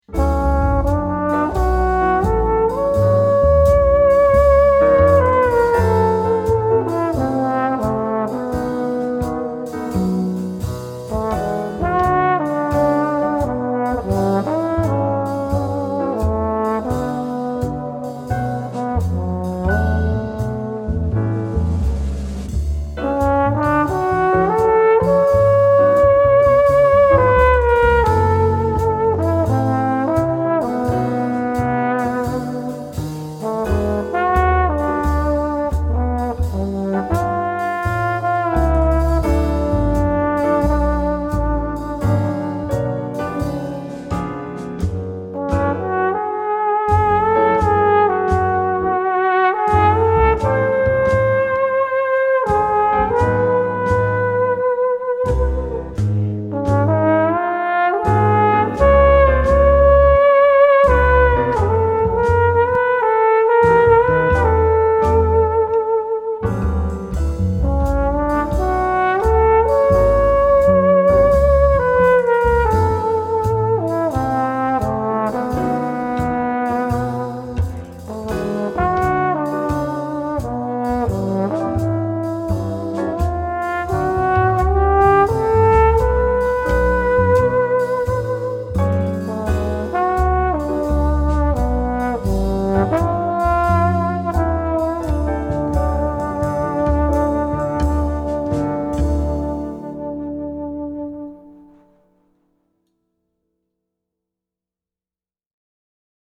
Tenortrombone - Jazz Orkestband